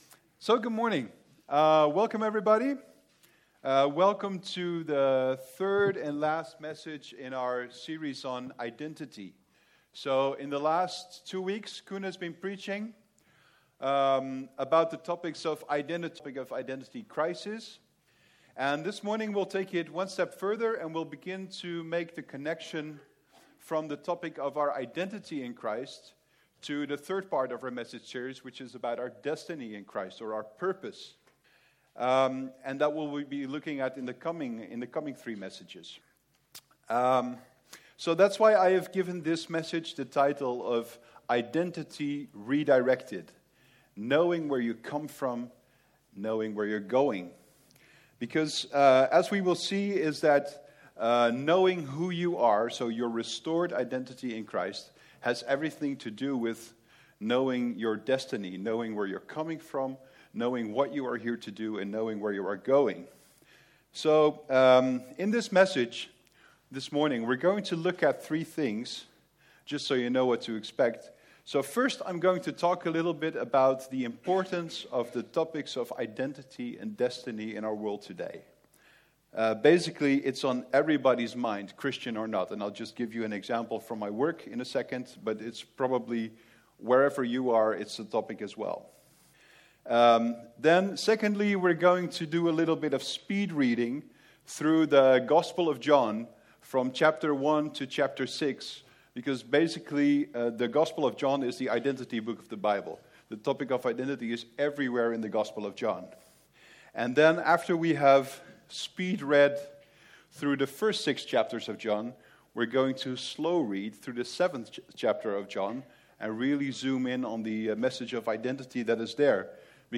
Vineyard Groningen Sermons Kingdom Life - Identity Redirected Play Episode Pause Episode Mute/Unmute Episode Rewind 10 Seconds 1x Fast Forward 30 seconds 00:00 / Subscribe Share Apple Podcasts Spotify YouTube RSS Feed Share Link Embed